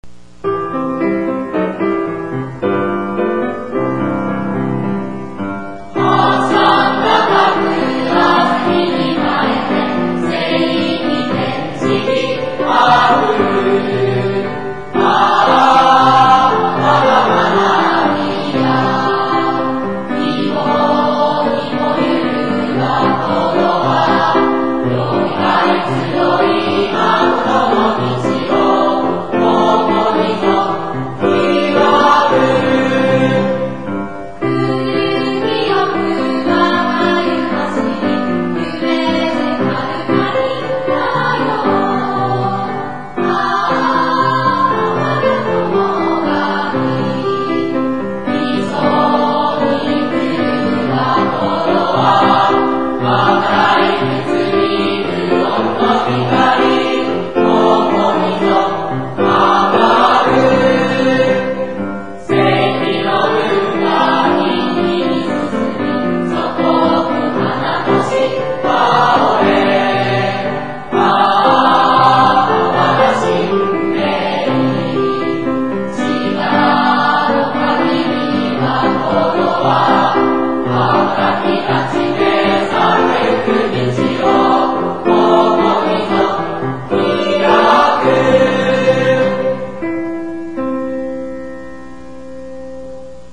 作詞　進明中学校　　作曲　石桁真礼生
gassyo.mp3